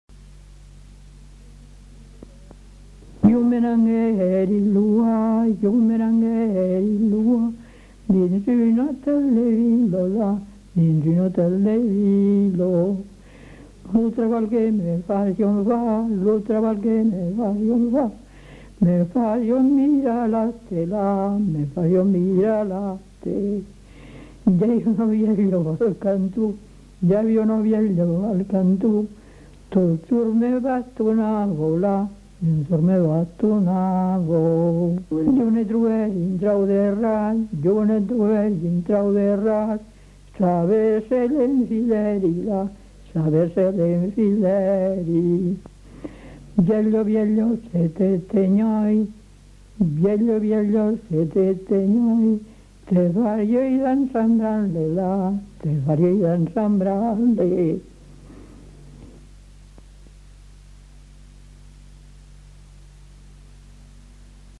Aire culturelle : Haut-Agenais
Lieu : Lot-et-Garonne
Genre : chant
Effectif : 1
Type de voix : voix de femme
Production du son : chanté